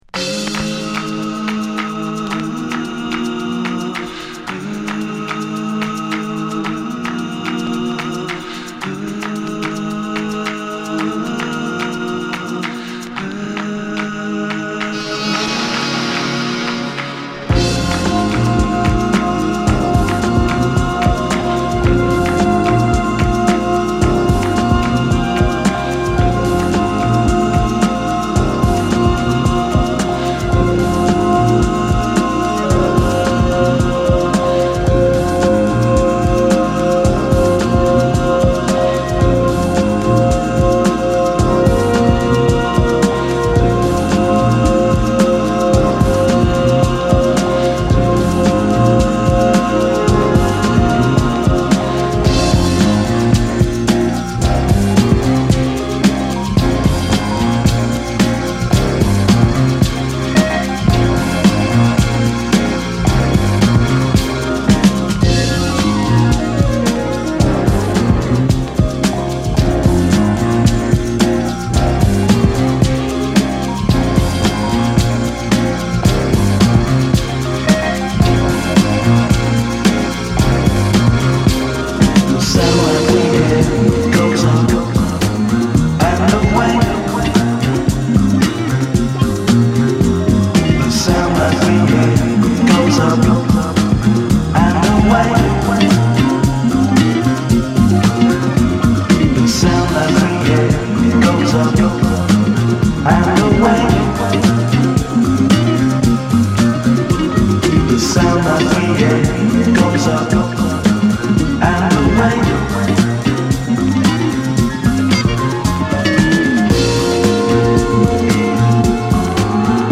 爽やかな数が吹き抜ける